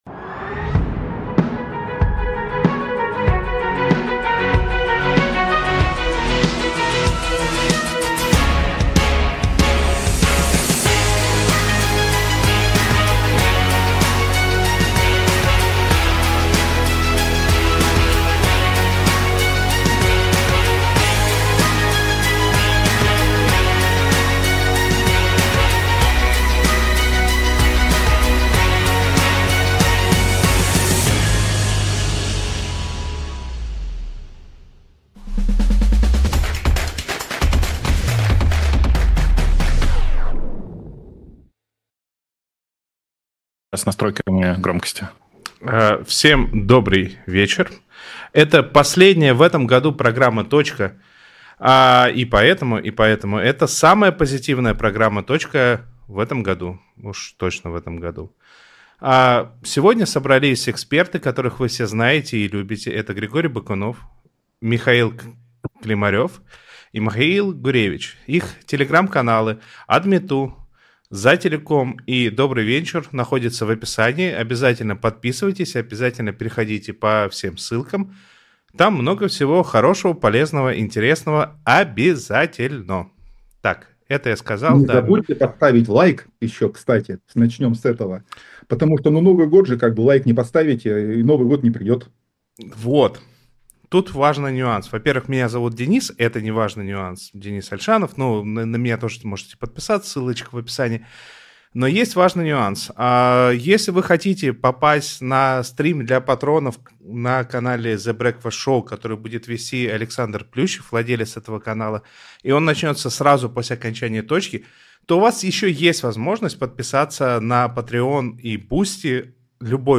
Эфир с экспертами про интернет и технологии в нашей жизни